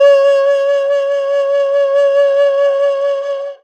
52-bi16-erhu-p-c#4.wav